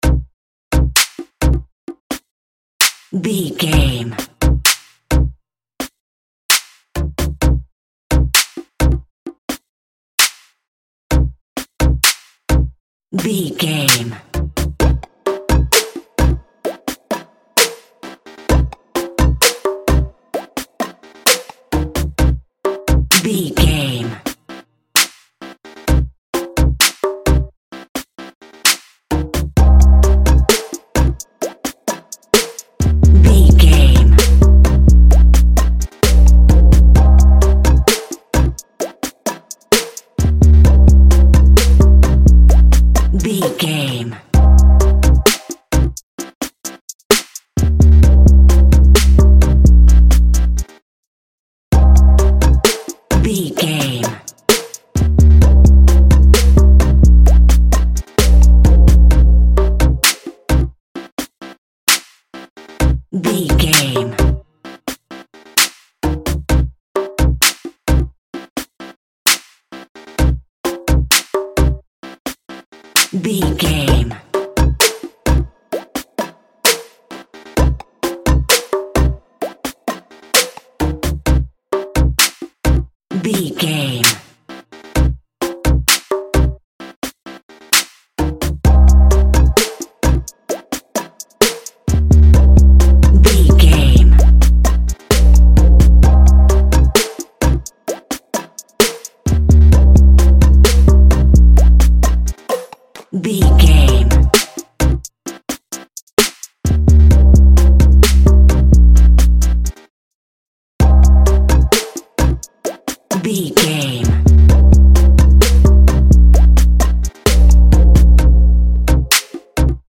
Category: Music